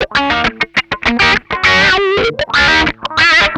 MANIC WAH 2.wav